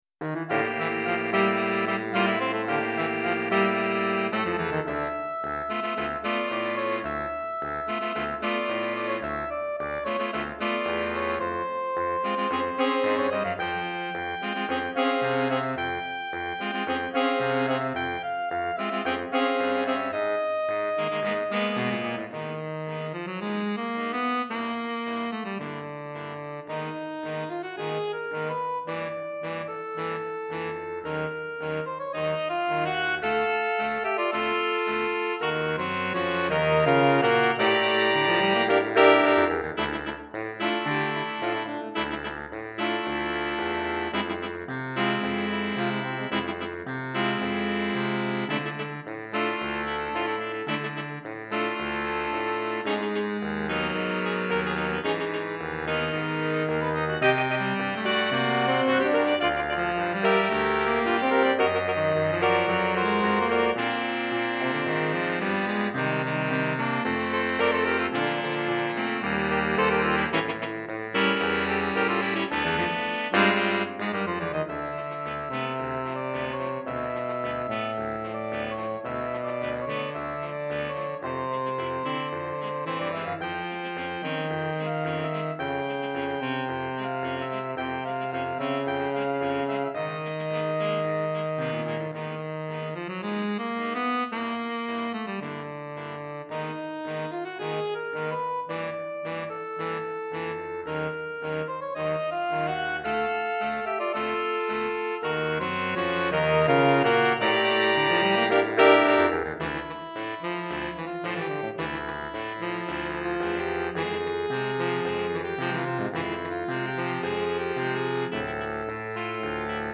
5 Saxophones